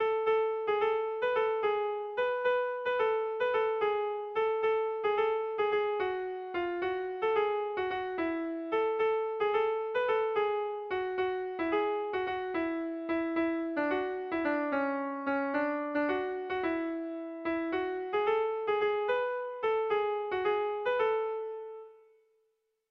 Kontakizunezkoa
Hamarreko txikia (hg) / Bost puntuko txikia (ip)
A1BA2DE